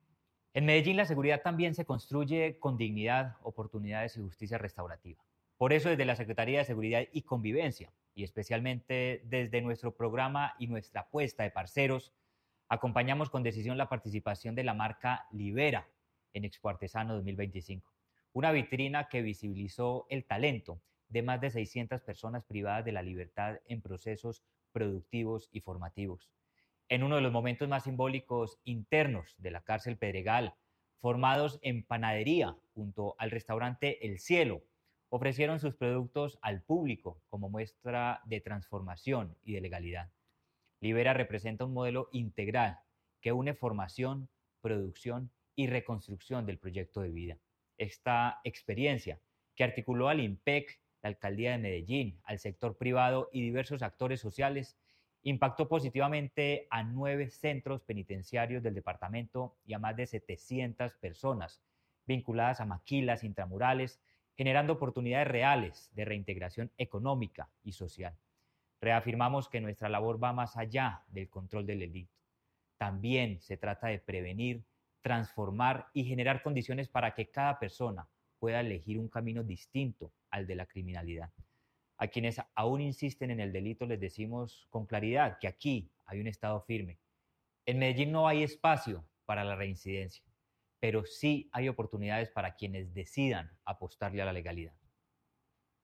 Declaraciones-del-secretario-de-Seguridad-y-Convivencia-Manuel-Villa-Mejia.-mercados-artesanos.mp3